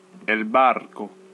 Ääntäminen
IPA : /ˈʃɪp/